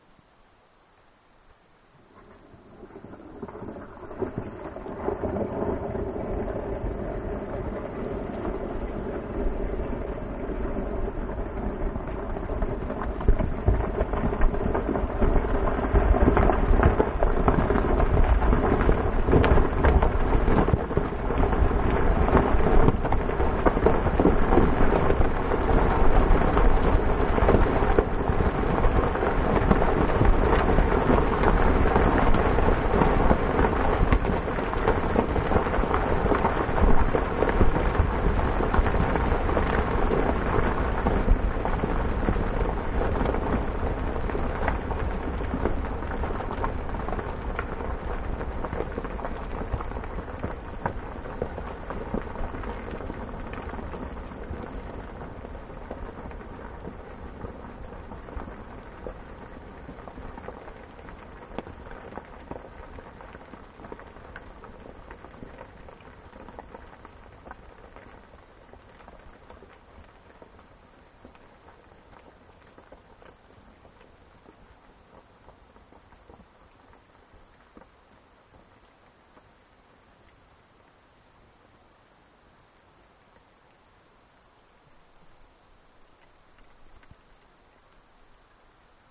A long, cascade of many small rocks tumbles down the steep valley walls of the upper Yentna Glacier.